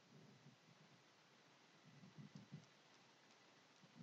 描述：自行车的后轮滴答作响
Tag: 自行车 壁虱